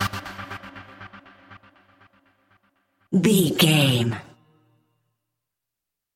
Epic / Action
Aeolian/Minor
drum machine
synthesiser
electric piano
Eurodance